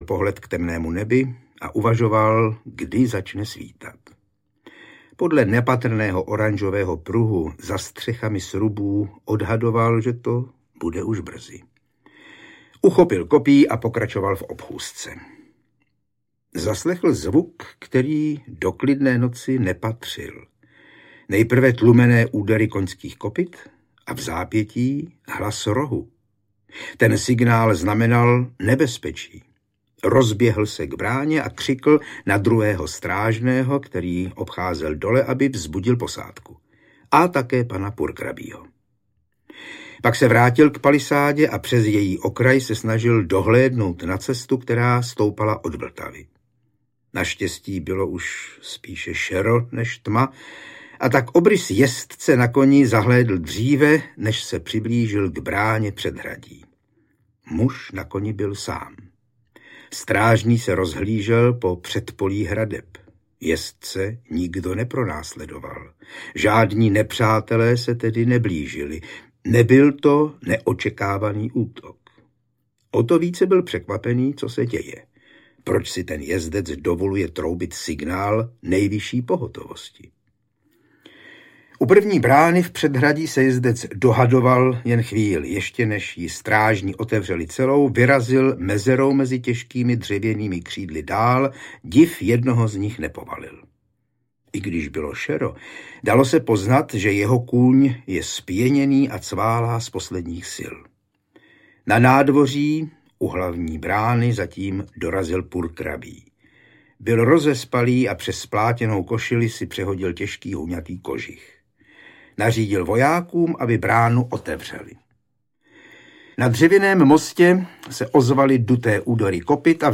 Přemyslovská epopej I.-IV. audiokniha
Ukázka z knihy
Čte Jan Hyhlík.